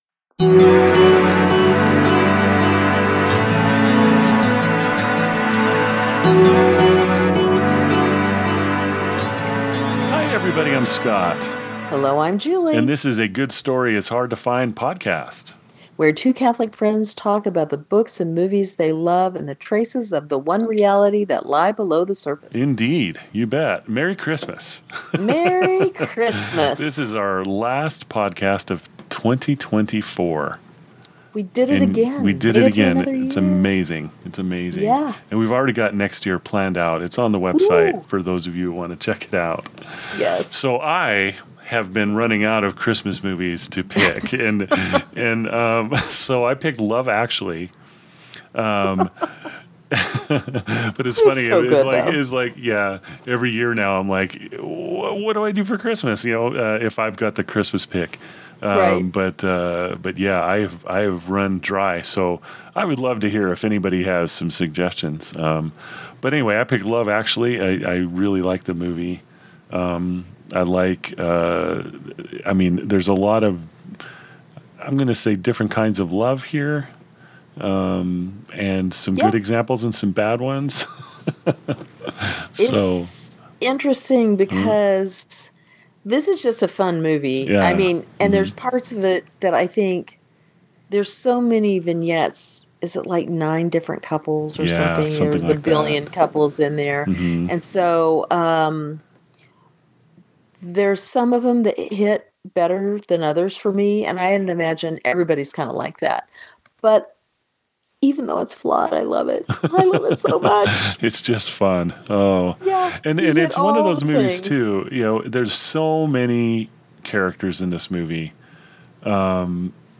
Two Catholics talking about books, movies and traces of "the One Reality" they find below the surface.